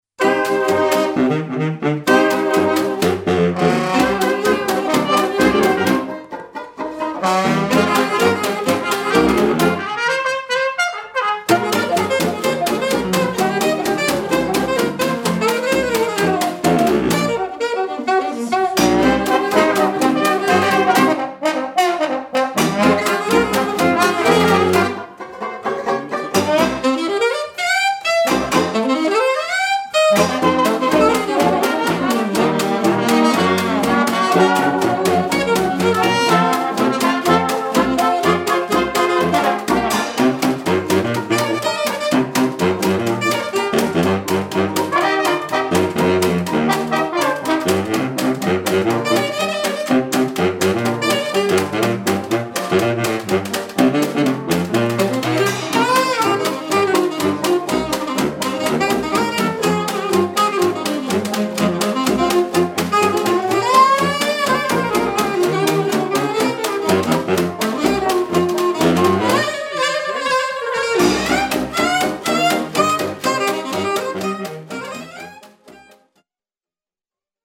cornet
saxophone alto, clarinette
trombone
piano
banjo
saxophone basse
batterie